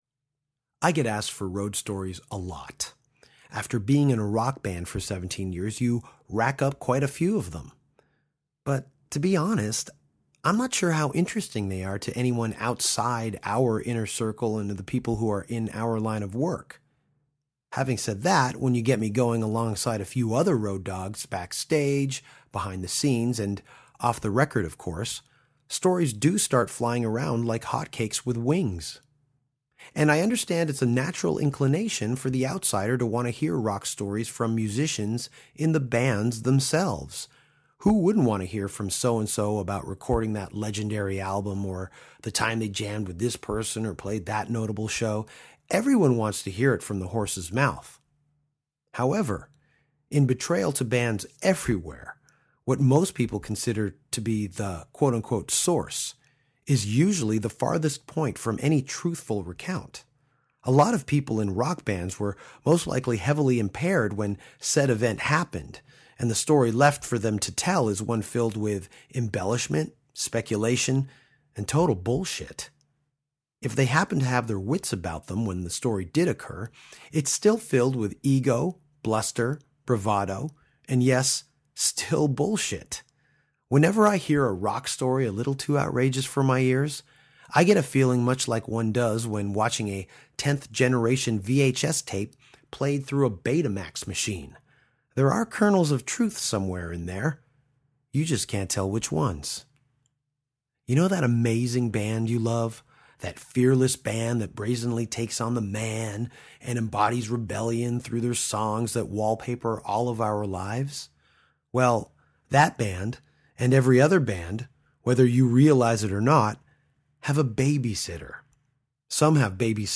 One of music’s top tour managers joins Danko for an in-depth discussion of the Bay Area 80’s Metal scene – Death Angel, Mordred and Paul Baloff.